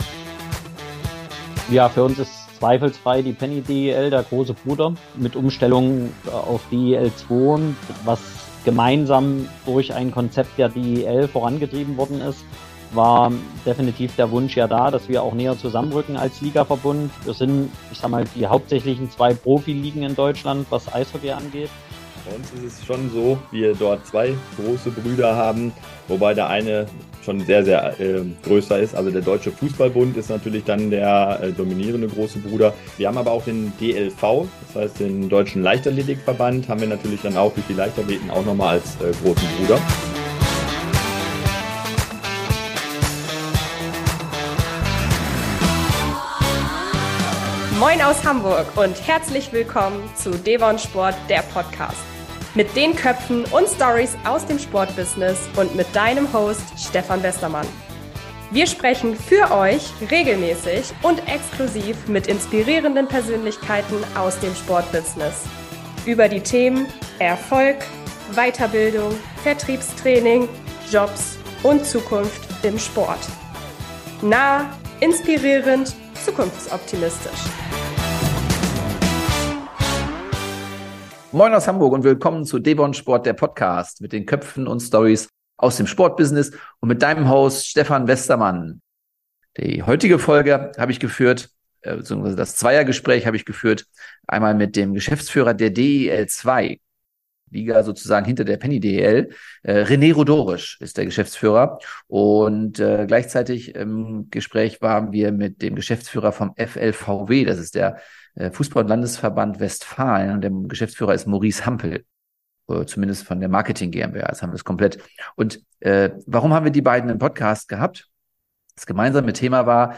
Erfolgreiche Vermarktung im Verband & Ligasystem mit “großem Bruder” - Im Interview